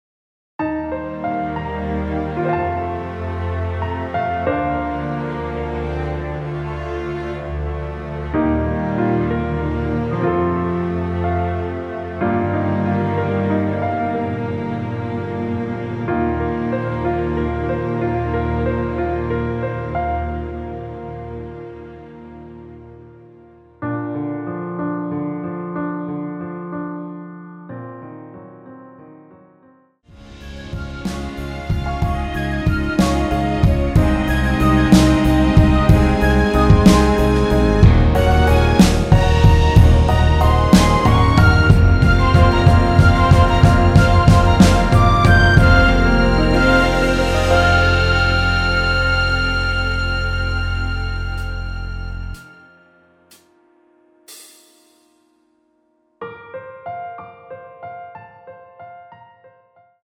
3분 14초경 무반주 후 노래 들어가는 부분 박자 맞추기 쉽게 카운트 추가하여 놓았습니다.(미리듣기 확인)
원키에서(-3)내린 MR입니다.
Bb
앞부분30초, 뒷부분30초씩 편집해서 올려 드리고 있습니다.